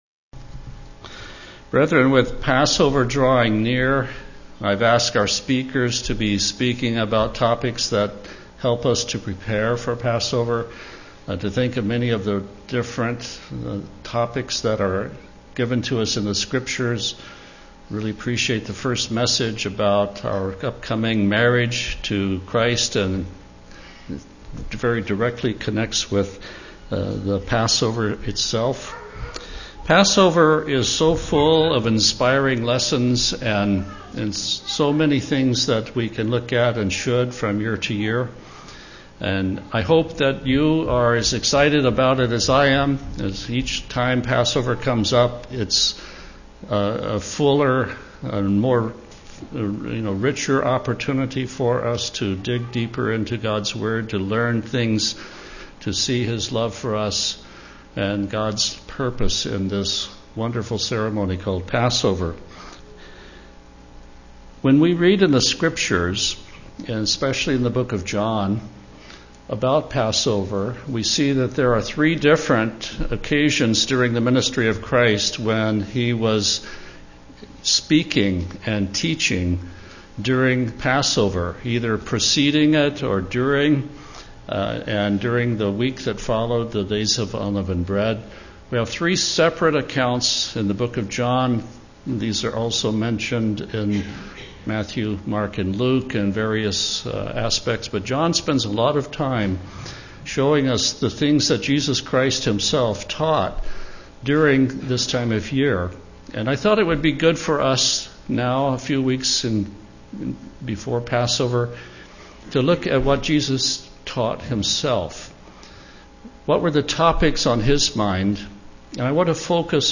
Sermons
Given in Tacoma, WA